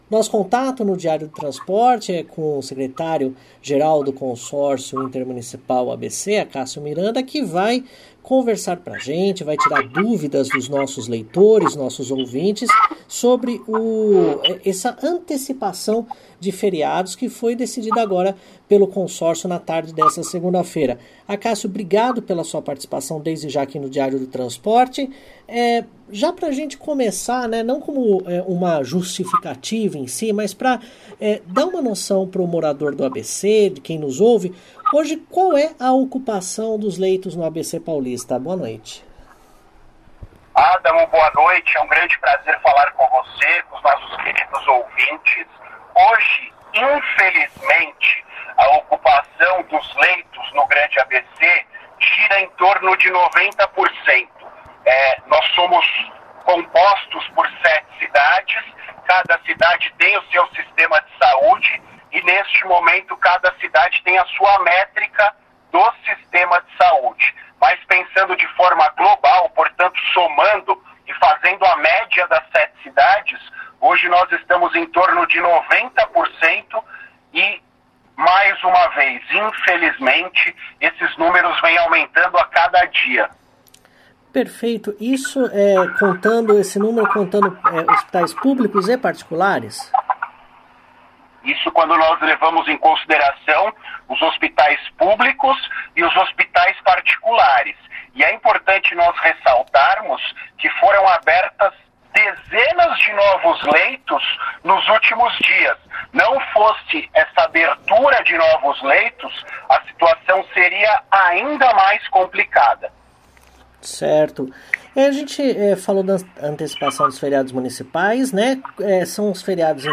Não haverá dia útil no Grande ABC entre 27 de março e 4 de abril deste ano. Em entrevista ao Diário do Transporte